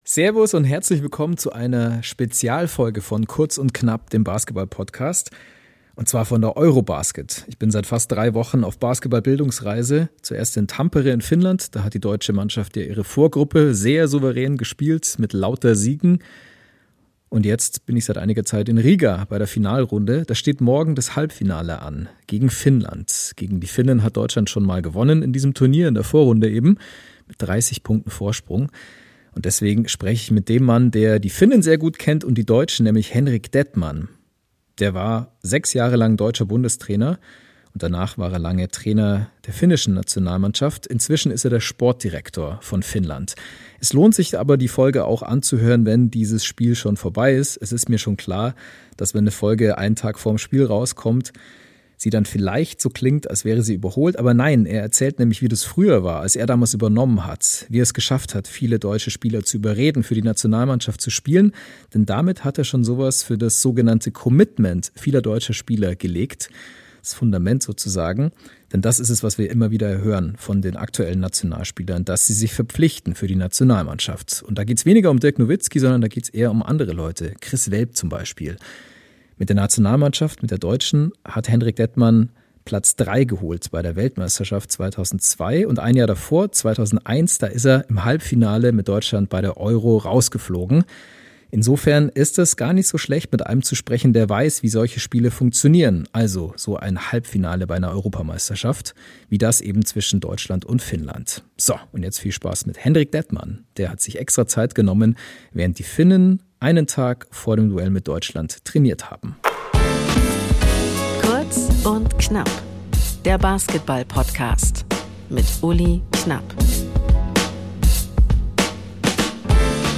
Spezialfolge von der Eurobasket 2025 in Riga!